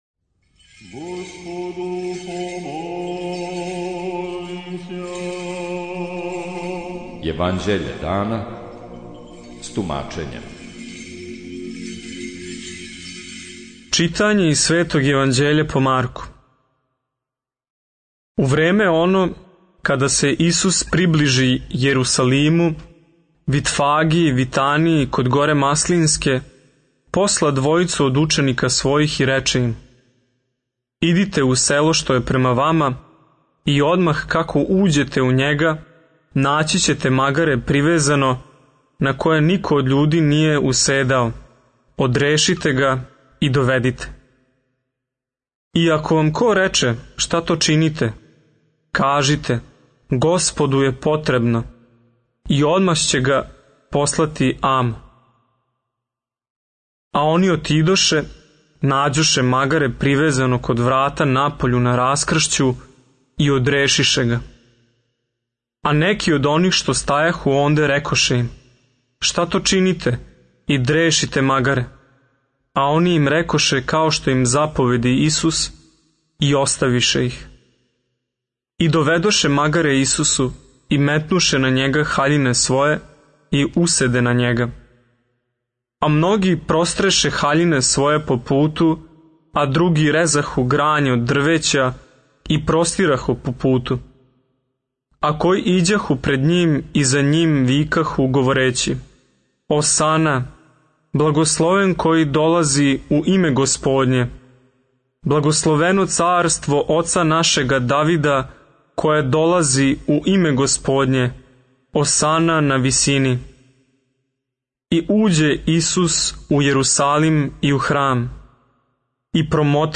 Читање Светог Јеванђеља по Матеју за дан 05.08.2023. Зачало 64.